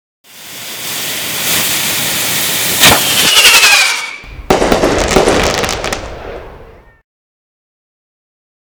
firework.ogg